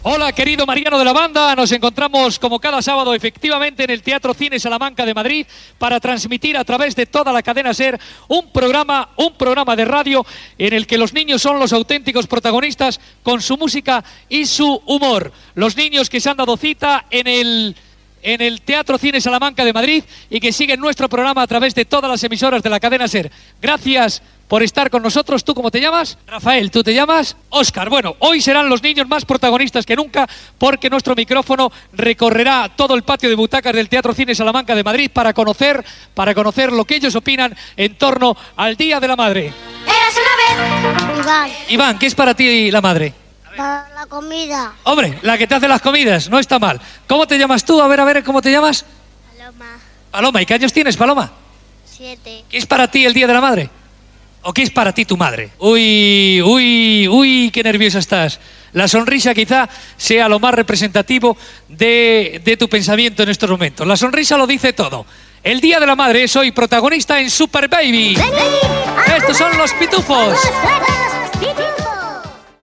Espai fet des del Teatro Cine Salamanca de Madrid. Presentació, intervenció d'infants que opinen sobre el Dia de la Mare i les mares i tema musical
Infantil-juvenil